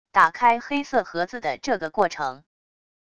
打开黑色盒子的这个过程wav音频